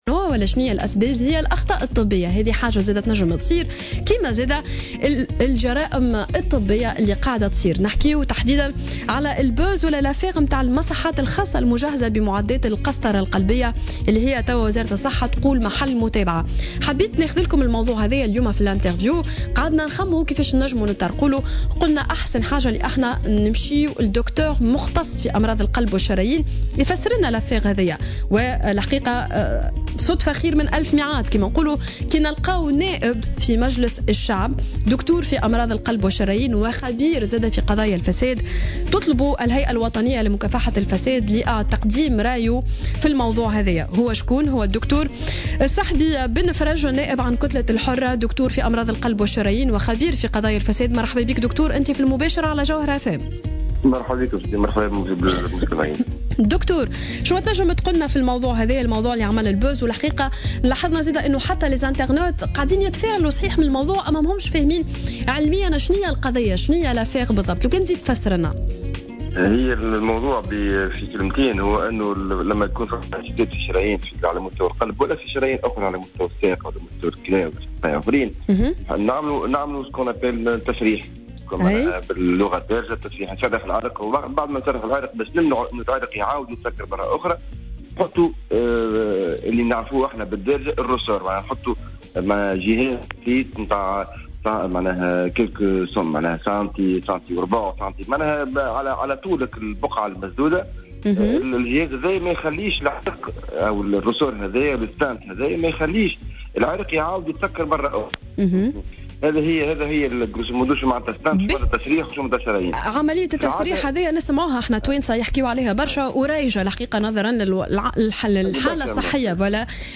أكد الدكتور في أمراض القلب والشرايين والخبير في قضايا الفساد الصحبي بن فرج في مداخلة له على الجوهرة "اف ام" صباح اليوم الجمعة أن قضية أجهزة القسطرة القلبية منتهية الصلوحية التي تم اكتشافها في بعض المصحات الخاصة تعود إلى شهر فيفري الماضي أي منذ 4 أشهر ومع ذلك وقع التعتيم عليها .